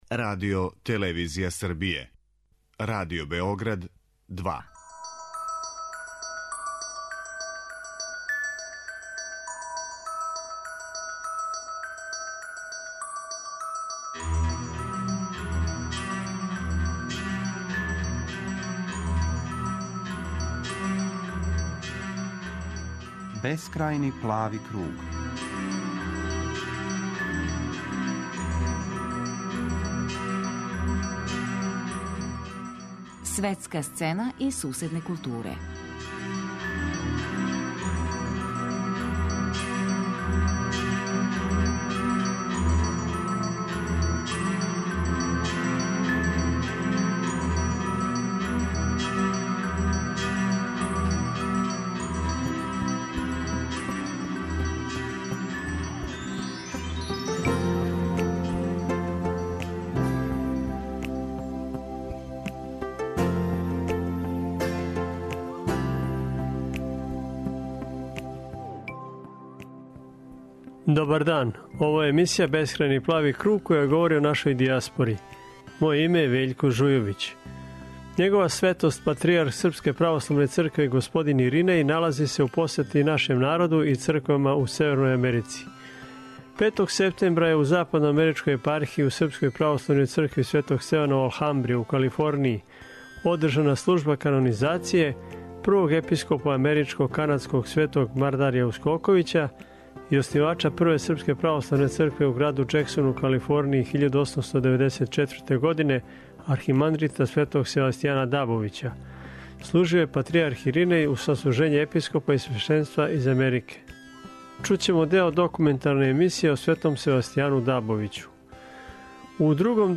Чућемо документарну репортажу о Севастијану Дабовићу.